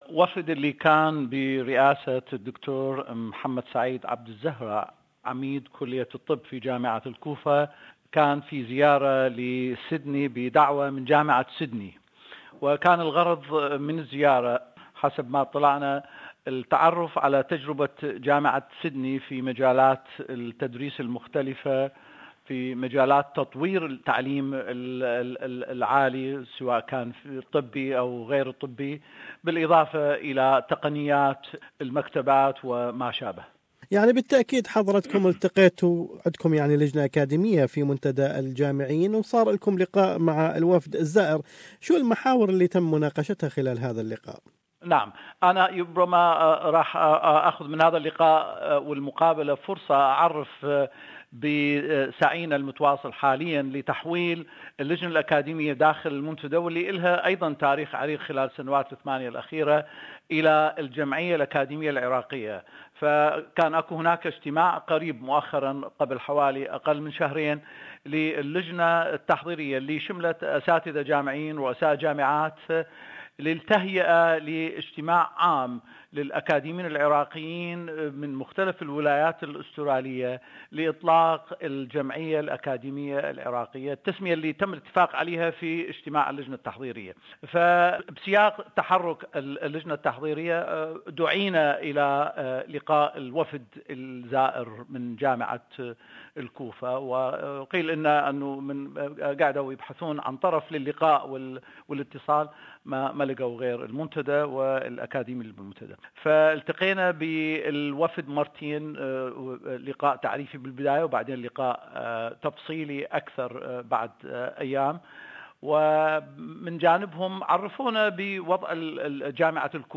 A delegation from Iraqi Kufa university has met with a number of Australian Iraqi academics in Sydney. More is in this interview